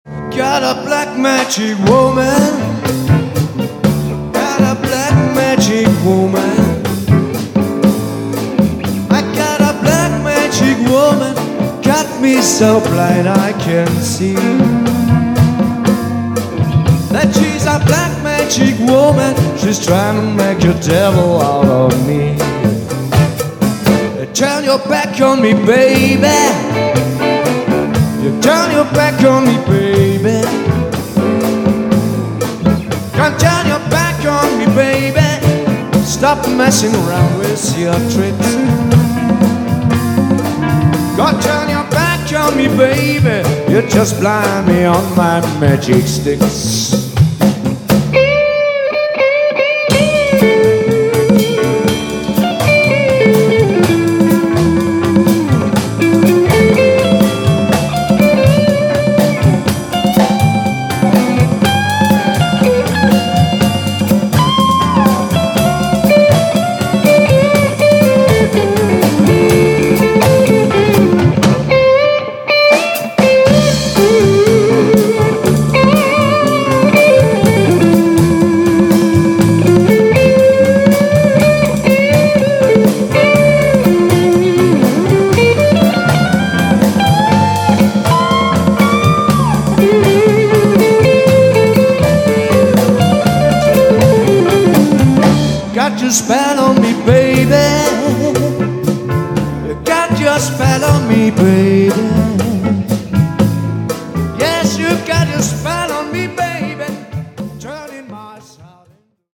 absolut Livemusic